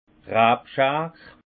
Haut Rhin d' Rabschar Français sécateur
Prononciation 68 Munster